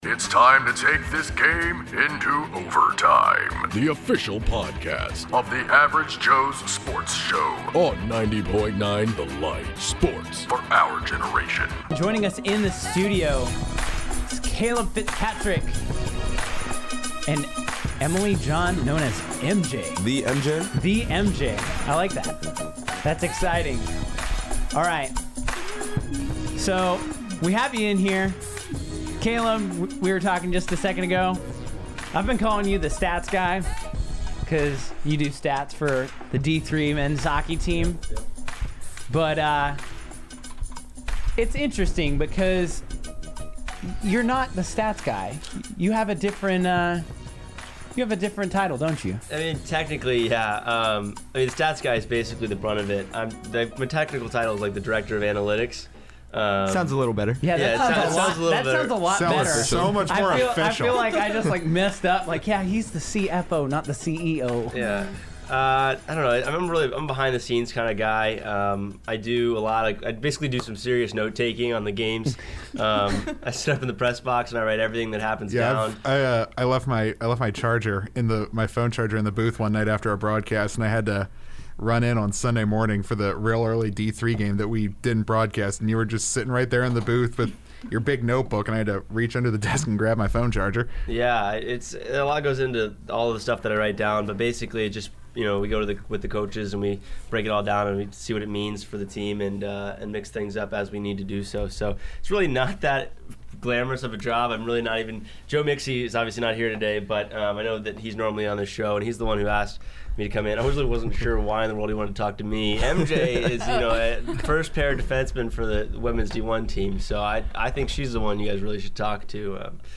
playoff hockey talk